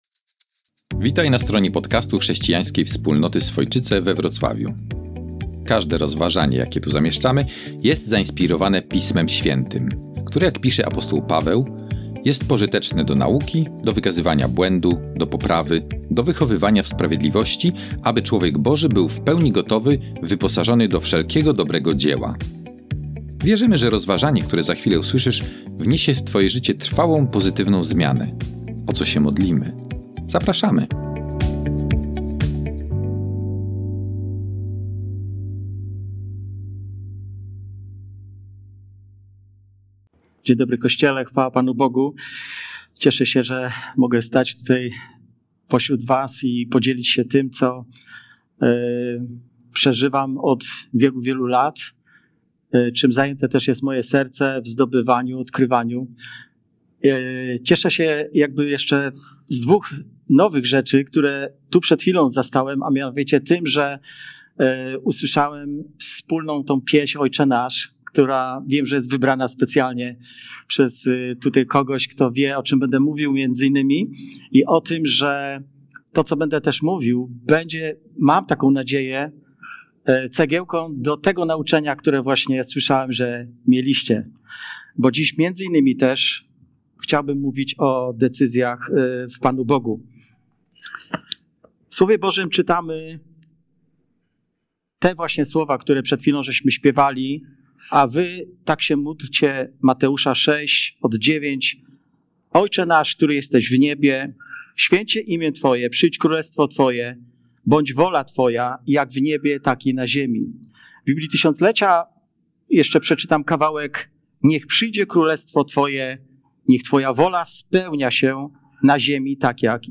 Nauczanie z dnia 8 września 2024